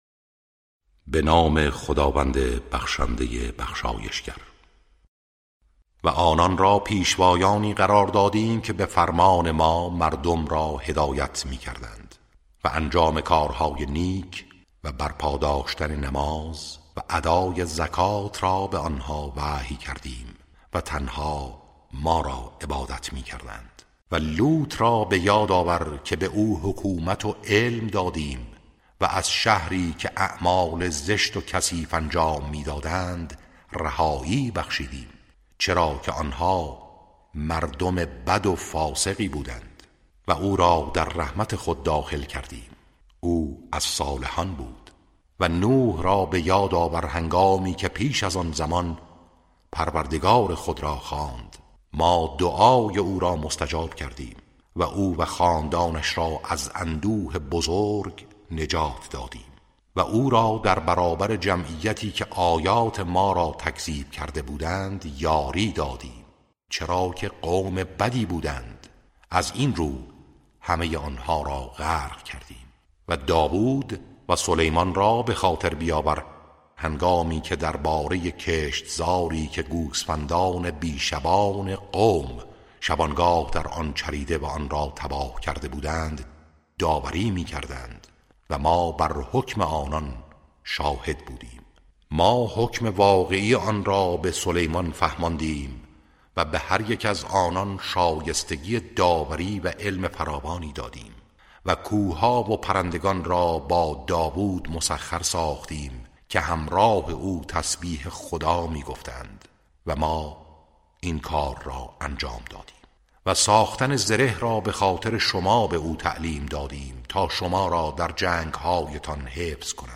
ترتیل صفحه ۳۲۸ سوره مبارکه انبیاء(جزء هفدهم)